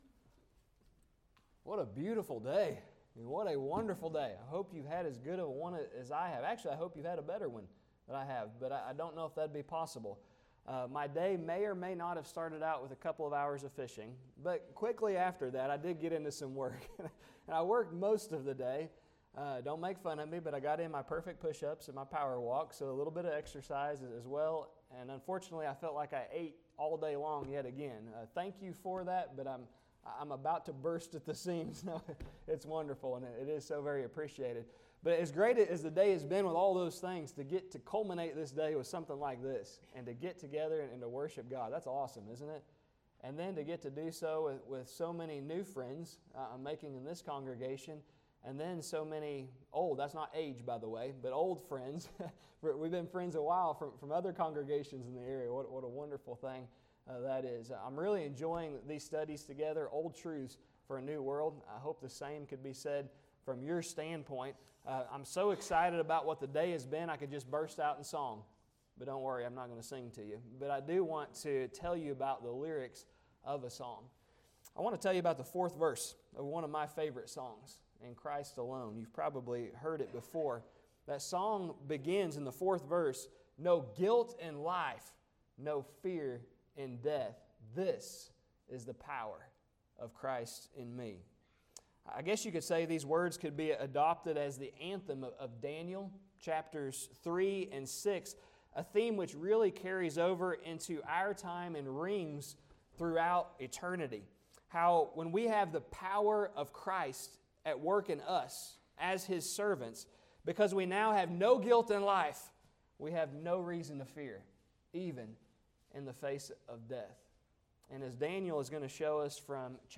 Daniel Service Type: Gospel Meeting When There’s No Guilt in Life …There’s NO FEAR OF THE LION!